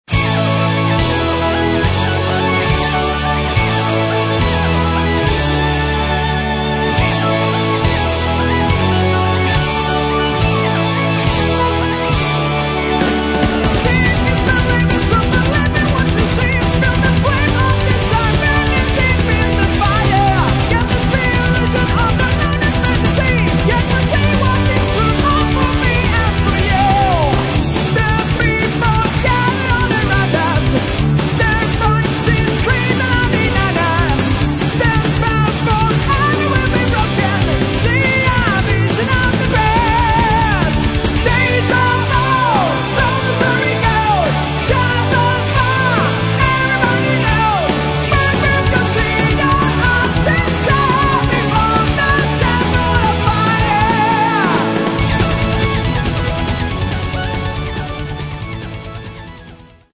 Metal
Постоянные «дуэли» гитар и клавиш.